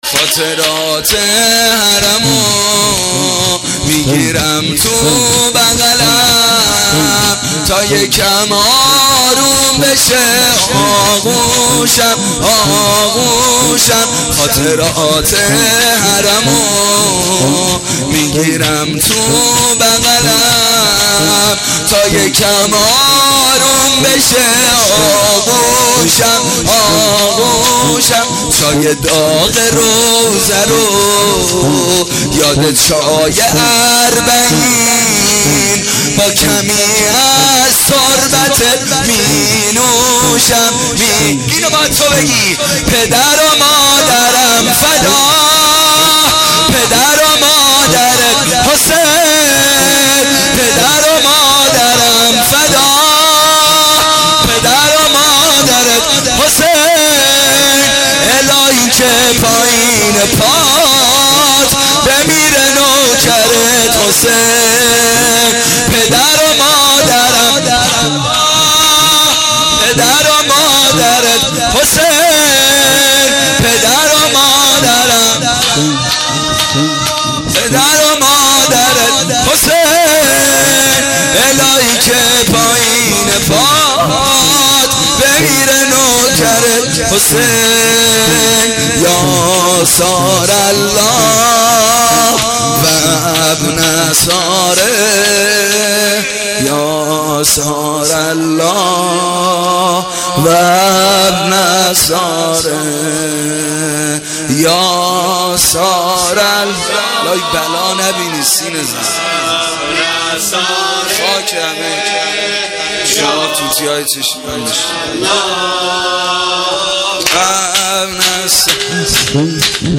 عزای اربعین حسینی